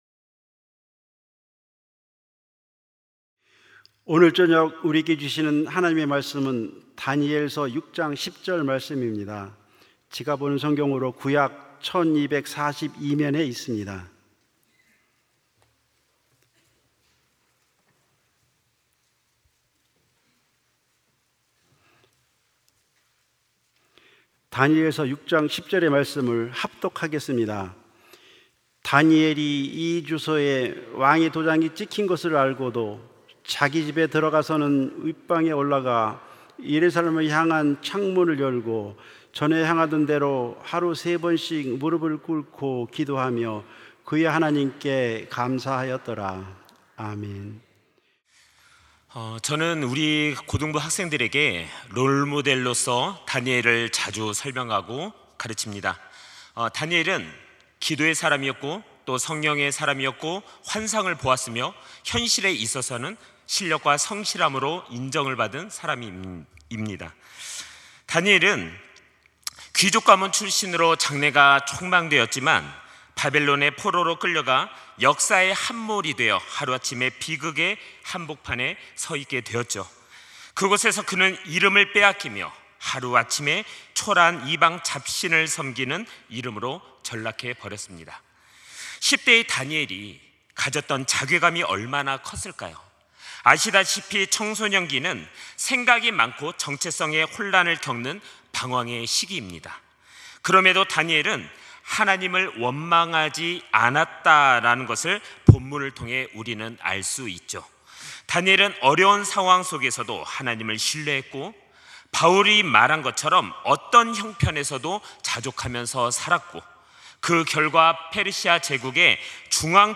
설교자